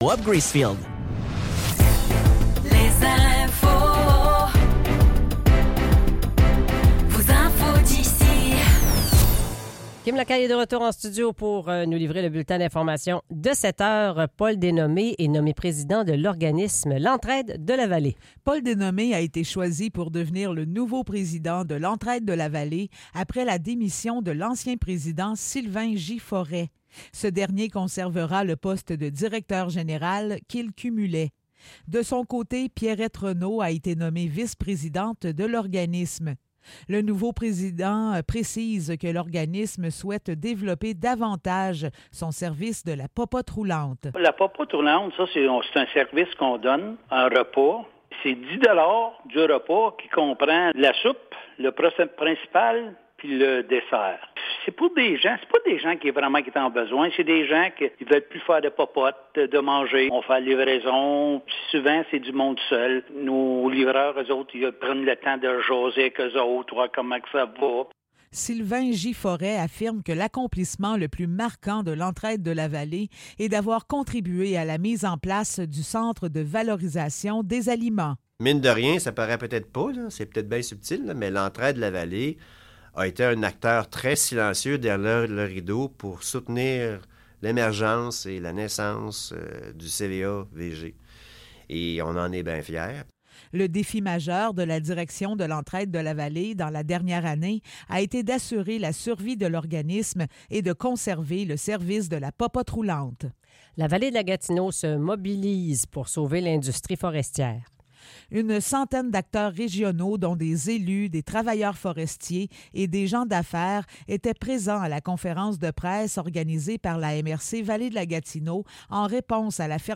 Nouvelles locales - 10 octobre 2024 - 7 h